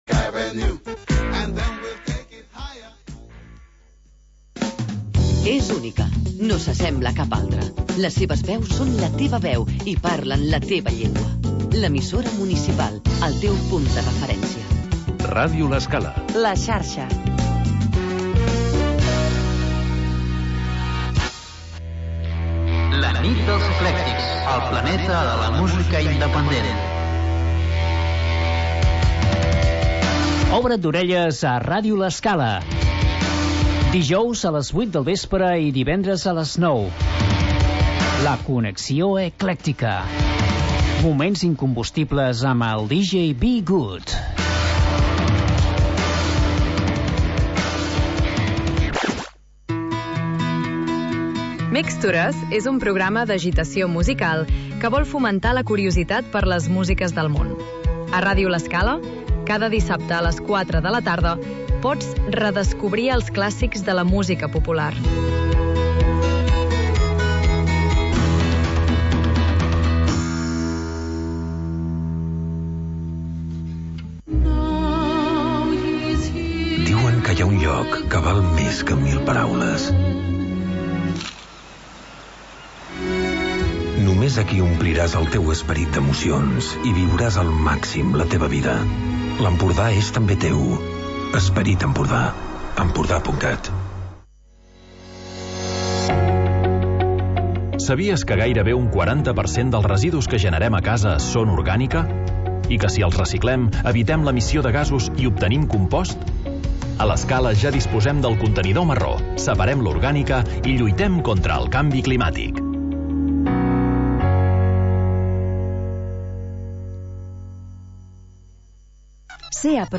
Magazín d'entreteniment per acompanyar el migdia